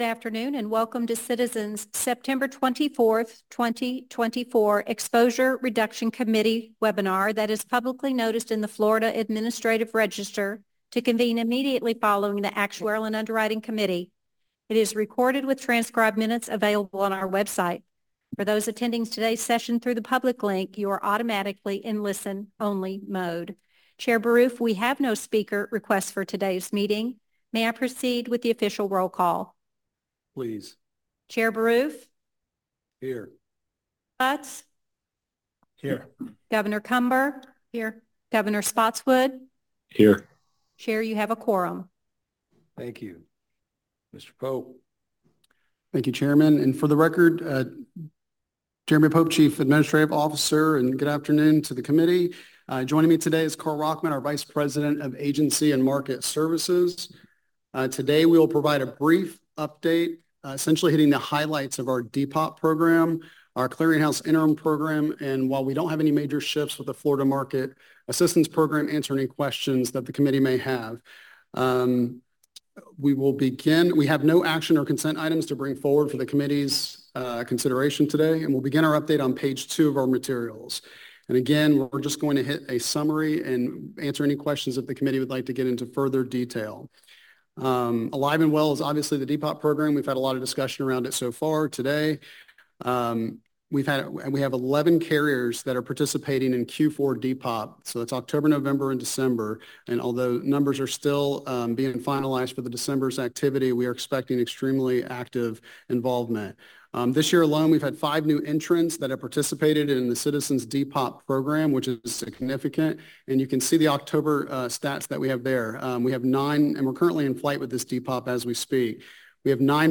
Meeting Details
Zoom Webinar
The Westin Lake Mary, 2974 International Parkway, Lake Mary, FL 32746
Teleconference